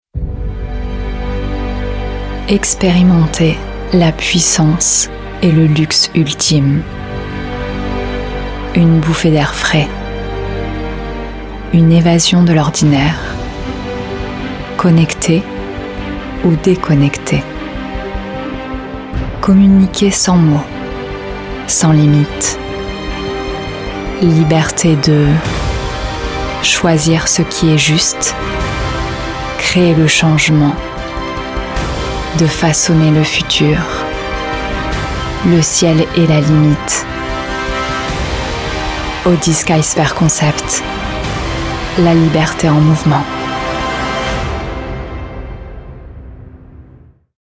Voix off
Demo Pub
20 - 36 ans